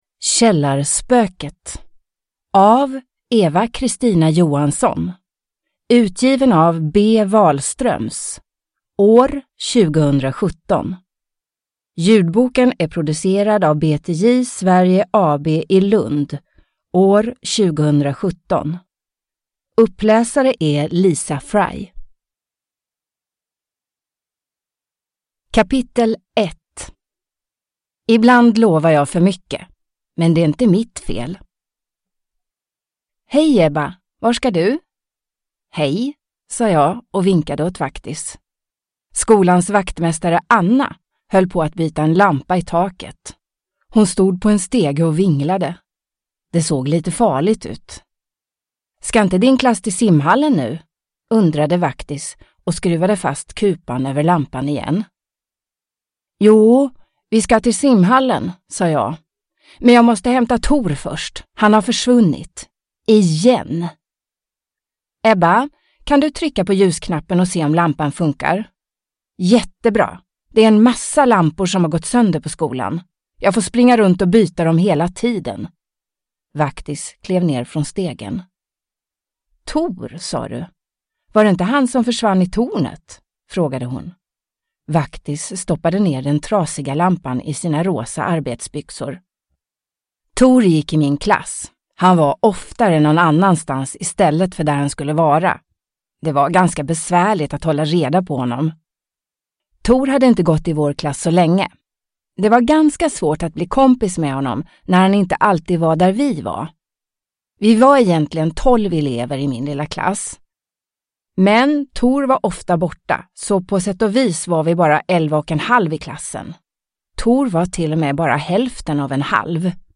Källarspöket – Ljudbok – Laddas ner